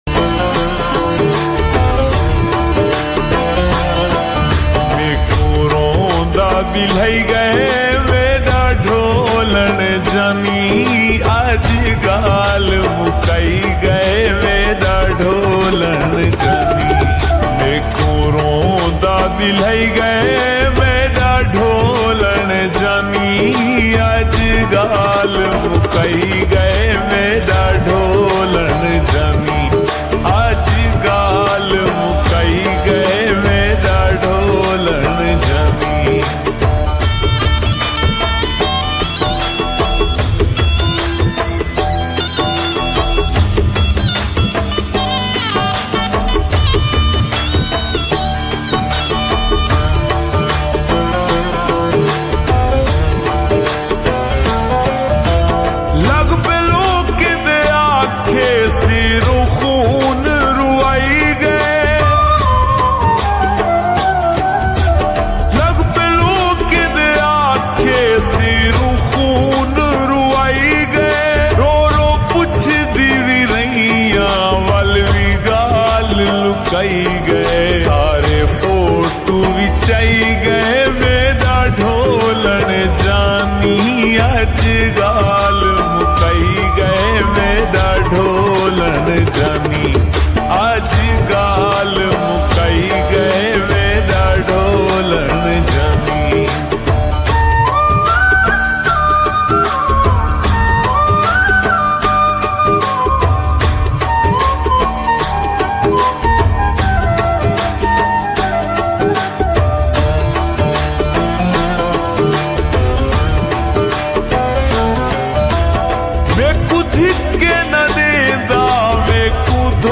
𝐒𝐥𝐨𝐰𝐞𝐝&𝐑𝐞𝐯𝐞𝐫𝐛
𝐏𝐮𝐧𝐣𝐚𝐛𝐢 𝐒𝐨𝐧𝐠